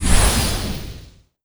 IceExplosion.wav